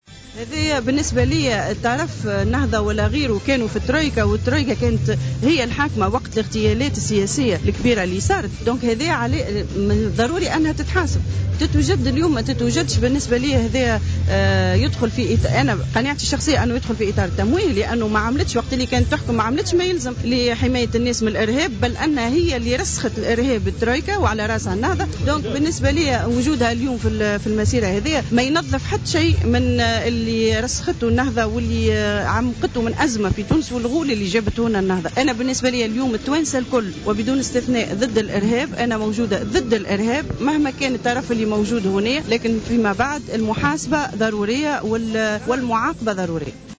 وأضافت الخلفاوي في تصريح لمراسل الجوهرة أف أم أن النهضة ساهمت في ترسيخ الإرهاب في تونس وتعميق الأزمة في تونس ومشاركتها في المسيرة لا تعني براءتها من الإرهاب في تونس.